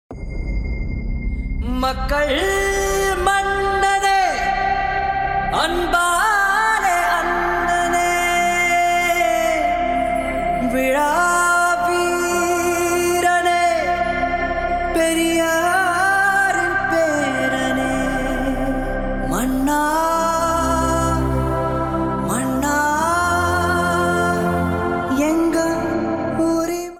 adds a royal, dignified feel to this ringtone